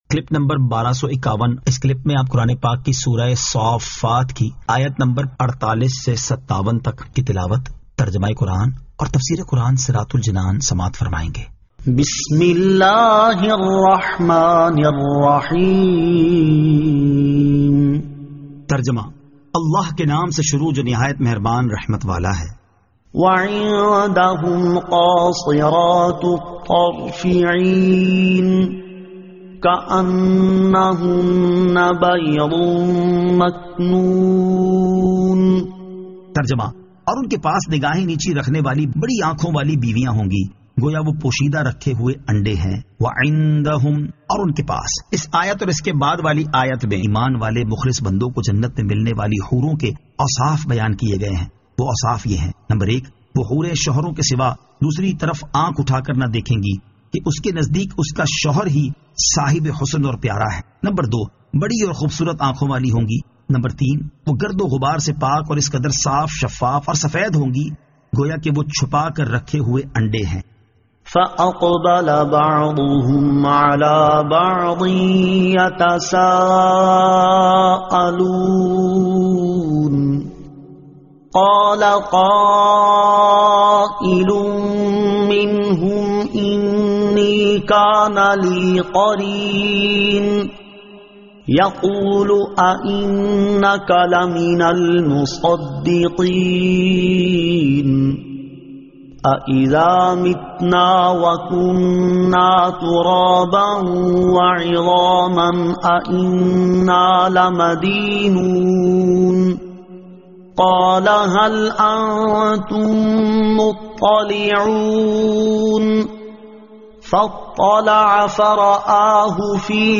Surah As-Saaffat 48 To 57 Tilawat , Tarjama , Tafseer
2023 MP3 MP4 MP4 Share سُوَّرۃُ الصَّافَّات آیت 48 تا 57 تلاوت ، ترجمہ ، تفسیر ۔